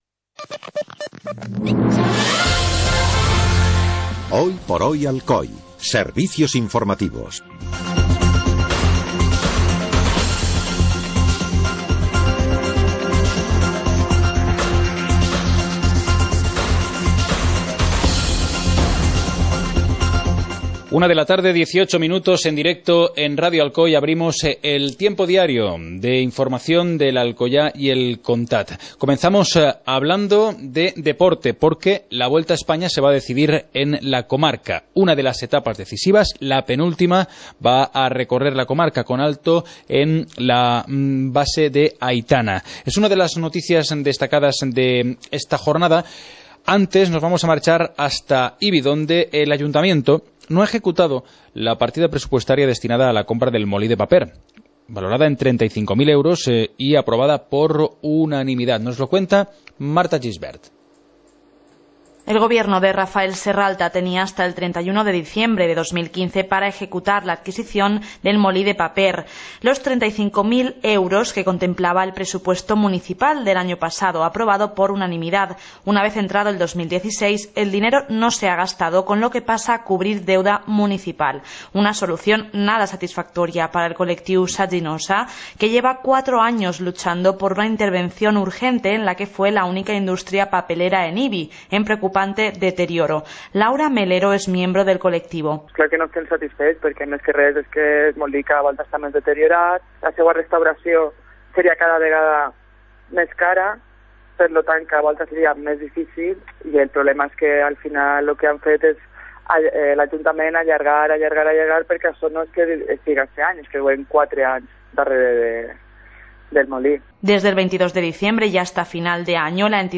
Informativo comarcal - viernes, 08 de enero de 2016